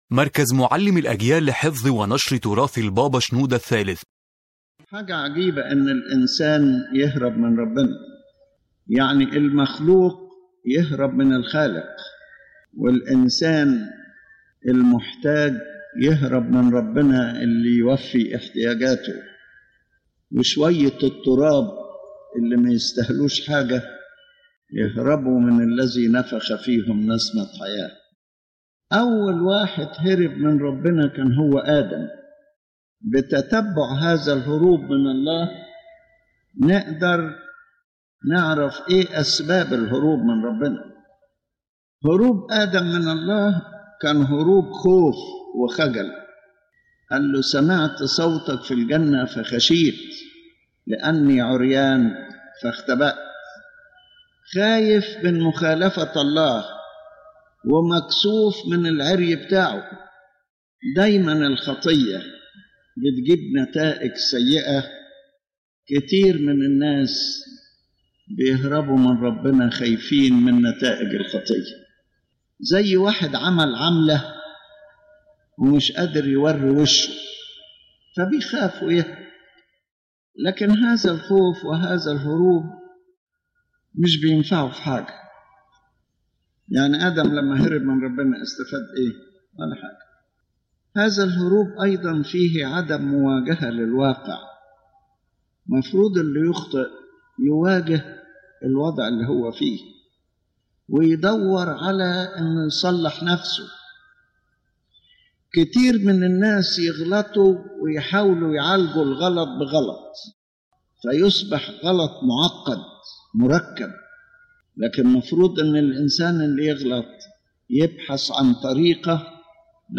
The lecture discusses the phenomenon of man fleeing from God, despite Him being the Creator and Giver, and explains the spiritual and psychological reasons that drive man to this escape, emphasizing that the only solution is repentance and returning with sincere love to God.